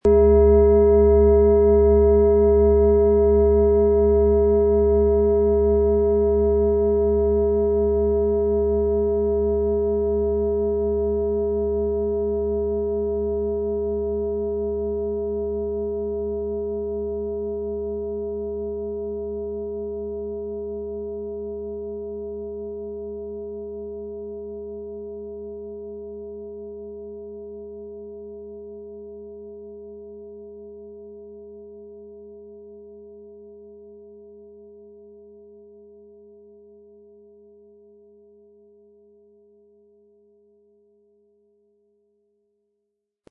Planetenschale® Vitalisieren und gestärkt werden & Löse Dich von alten Strukturen mit Mars & Uranus, Ø 30 cm inkl. Klöppel
Diese Schale ist erstklassig und klingt extrem lange, eine klare Kaufempfehlung!
• Mittlerer Ton: Uranus
Um den Original-Klang genau dieser Schale zu hören, lassen Sie bitte den hinterlegten Sound abspielen.
PlanetentöneMars & Uranus
MaterialBronze